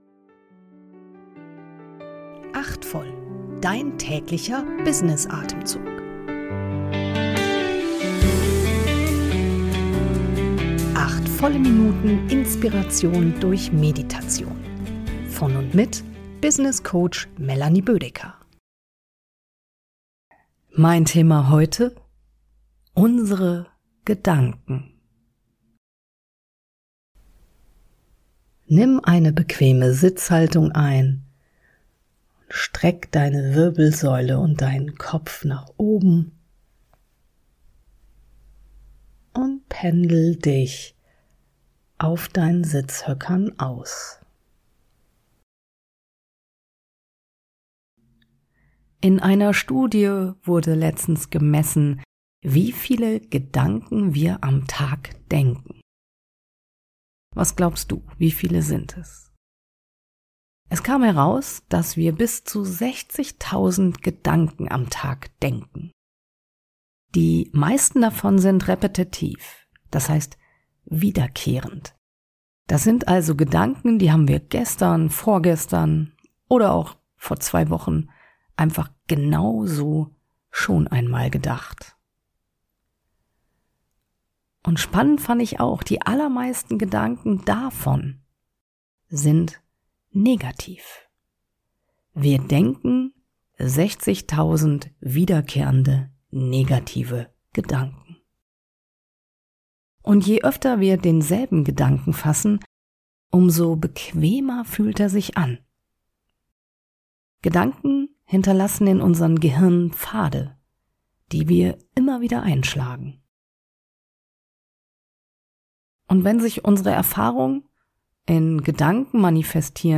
durch eine geleitete Kurz-Meditation gebrauchen können.